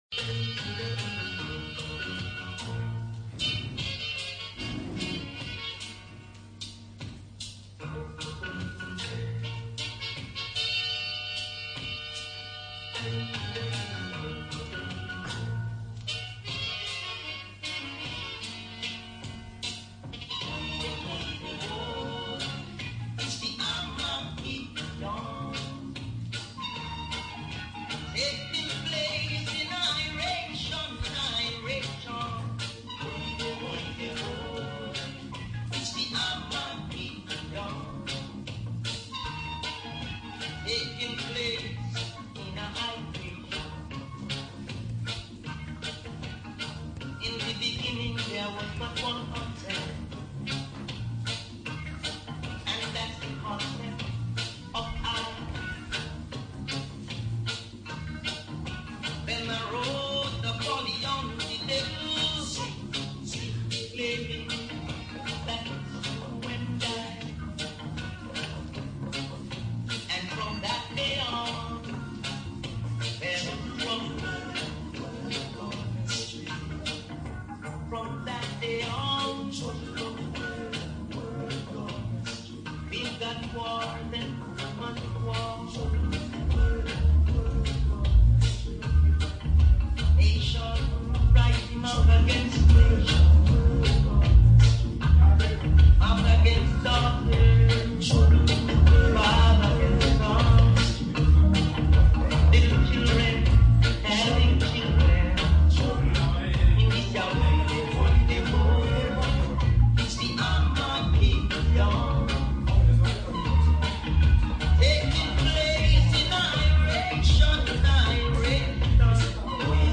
IN SESSION LAST SATURDAY IN AMSTERDAM !
Peacefull and irie vibes in the place ! Old School vibes !